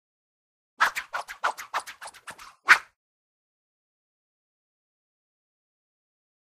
Sword: Multiple Swish; About Eleven Nonstop Swishes From Rapidly Swinging Sword Back And Forth. Close Perspective. Whoosh.